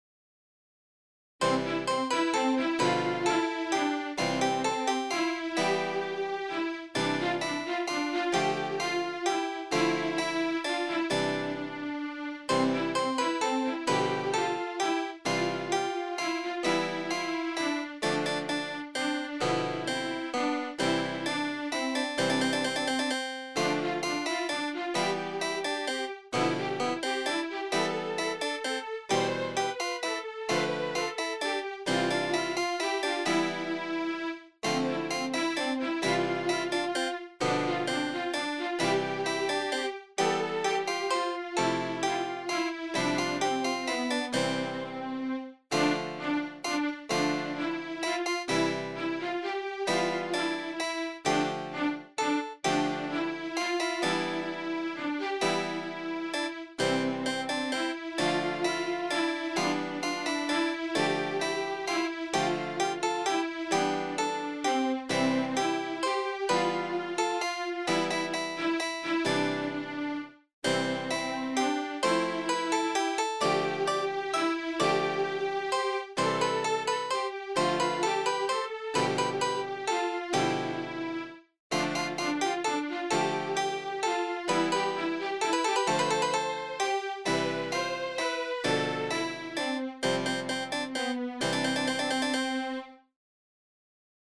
BGM
クラシック穏やか